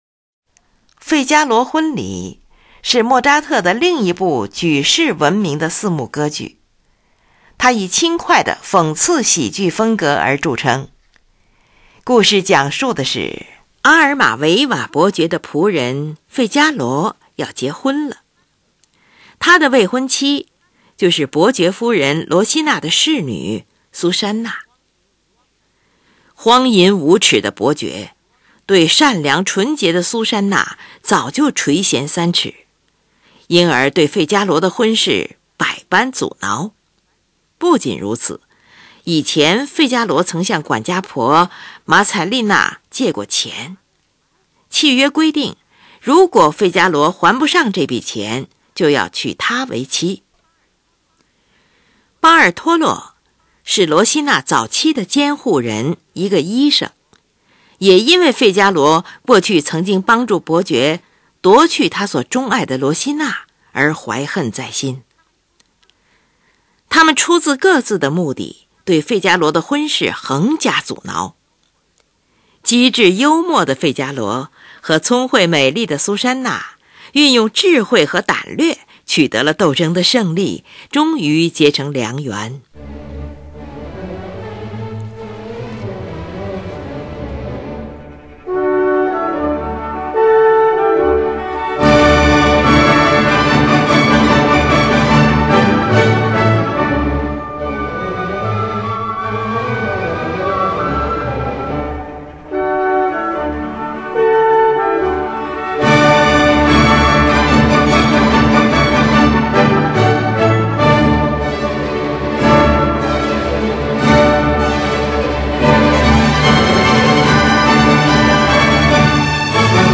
旋律是由木管和弦乐器来担任的。
再现部中，主副部调性统一，都在主调——D大调上，在热烈的气氛中结束了全曲。